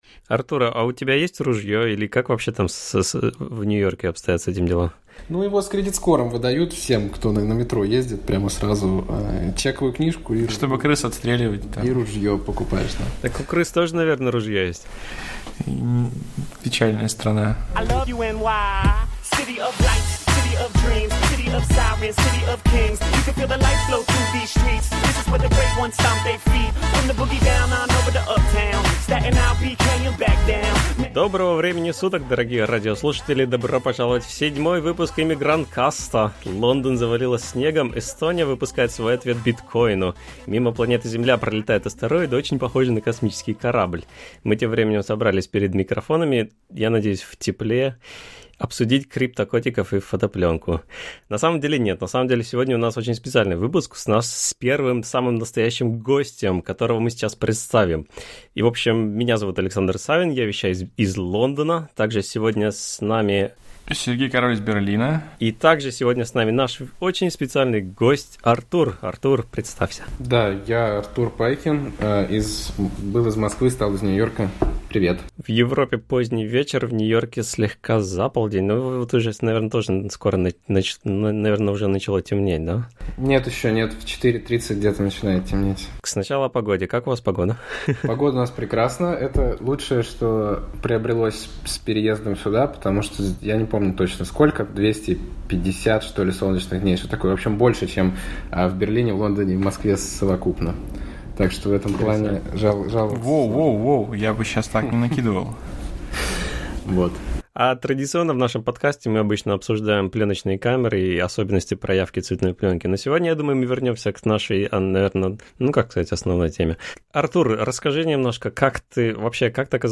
Подкаст о жизни в Лондоне, Берлине и Нью-Йорке, пленочной фотографии, инди-интернете, лодках, брекзите и дронах. На проводе гости от Сингапура и Гоа до Франции и США — от дизайнера шрифтов до пилота самолета.